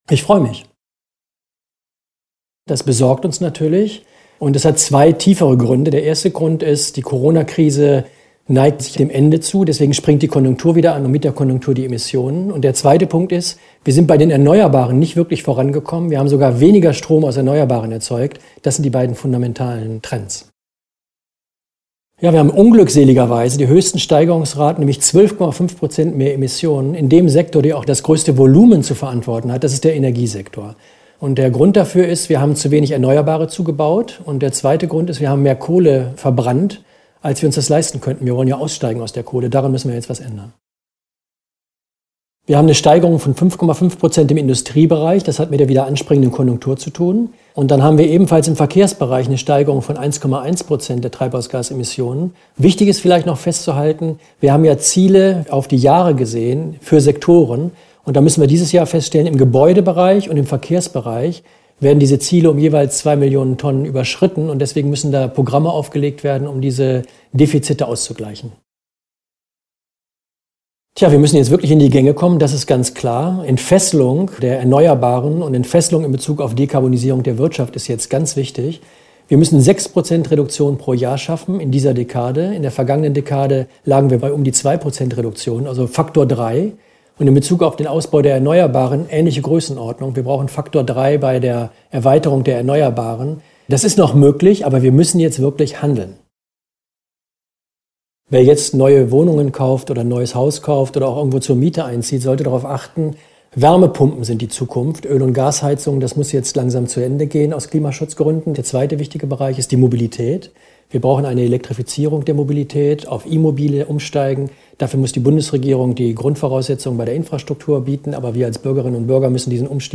UBA-Präsident Dirk Messner mit Hintergründen zur aktuellen Entwicklung
Interview: 2:56 Minuten